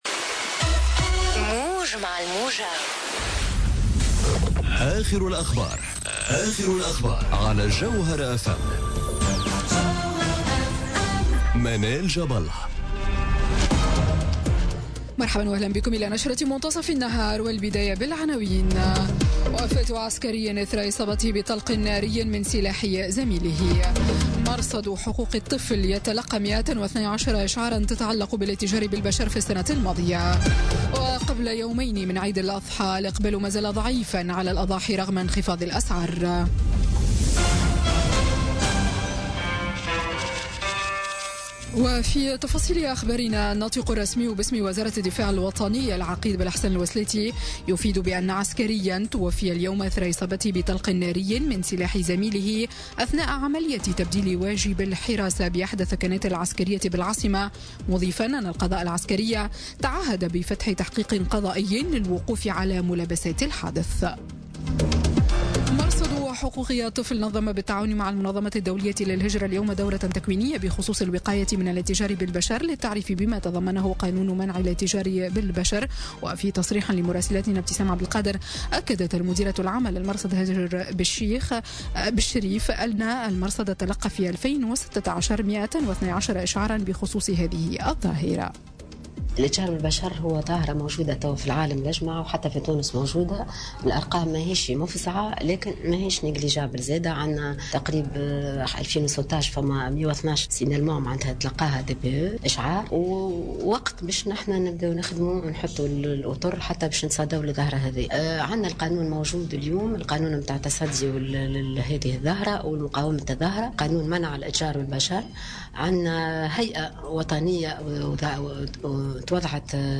نشرة أخبار منتصف النهار ليوم الثلاثاء 29 أوت 2017